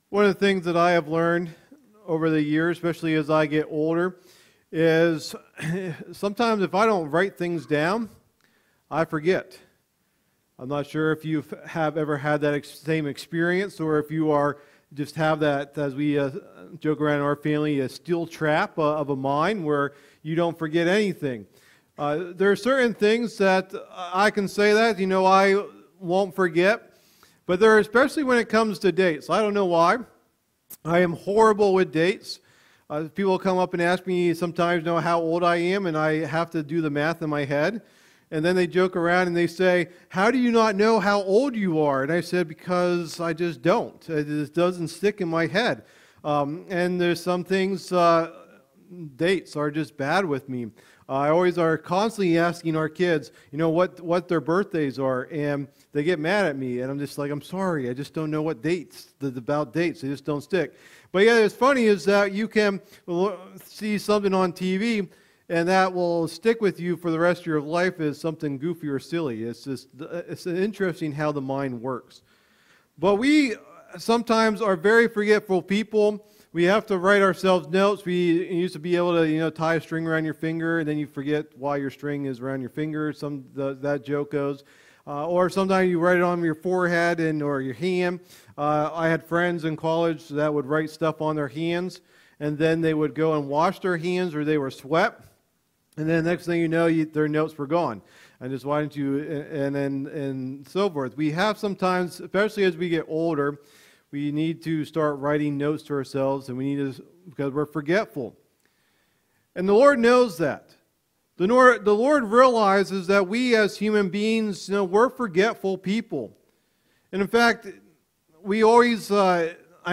Sunday Morning Teachings | Bedford Alliance Church